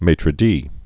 (mātrə dē, mātər)